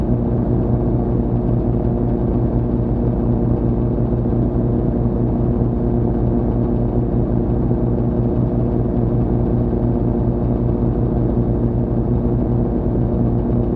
Engine.ogg